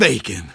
Engineer says BACON Download for iPhone